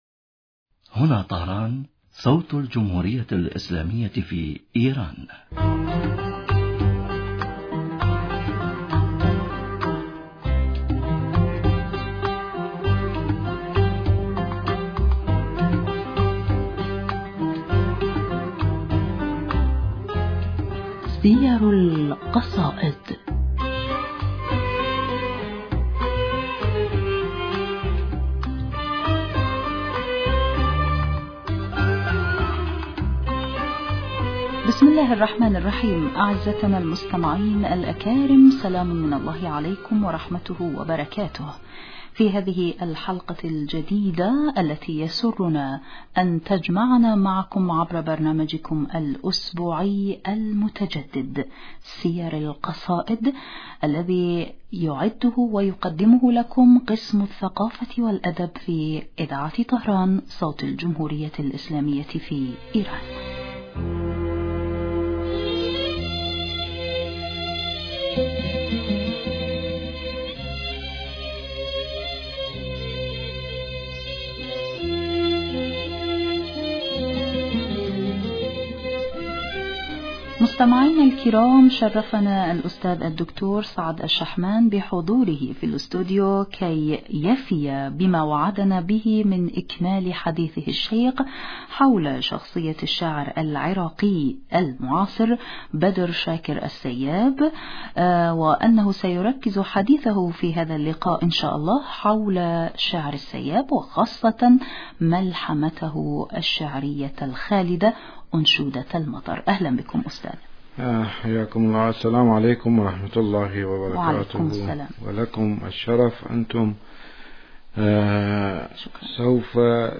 بسم الله الرحمن الرحيم أعزتنا المستمعين الأكارم سلام من الله عليكم ورحمته وبركاته في هذه الحلقة الجديدة التي يسرنا أن تجمعنا معكم عبر برنامجكم الأسبوعي المتجدد سير القصائد الذي يعده ويقدمه لكم قسم الثقافة والأدب في اذاعة طهران صوت الجمهورية الاسلامية في ايران.